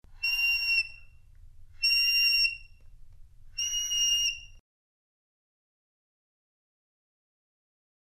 Teeth on Reed
for solo clarinet